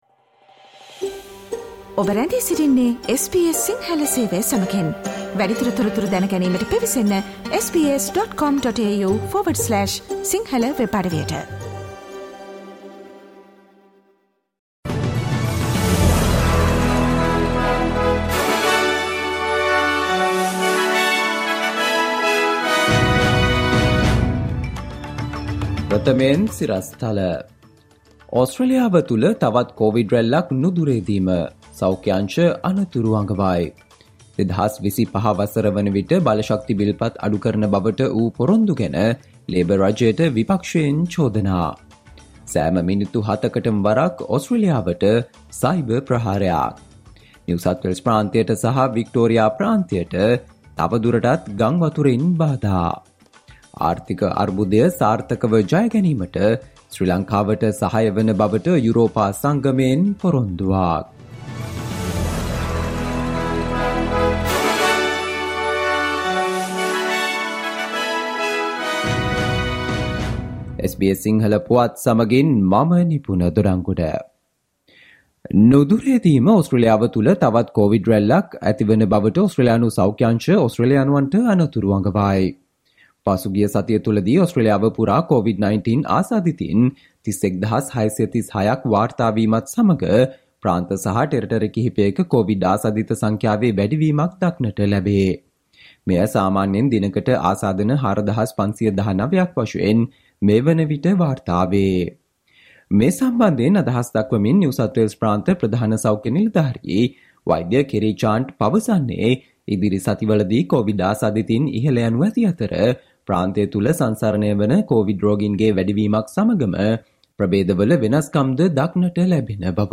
Listen to the SBS Sinhala Radio news bulletin on Friday 04 November 2022